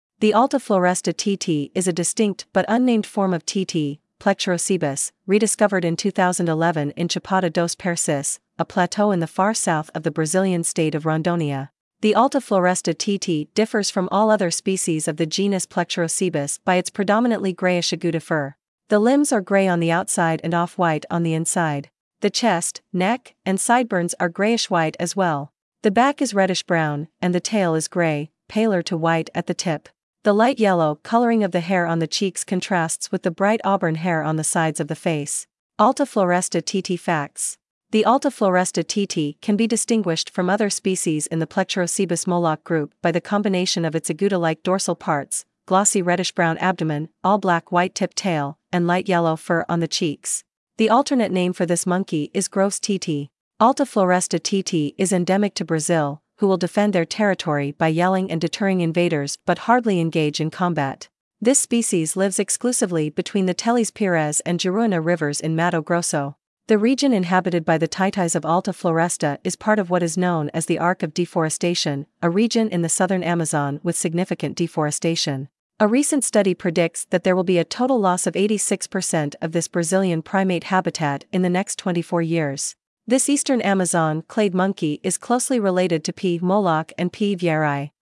Alta Floresta Titi Monkey
Alta-Floresta-titi.mp3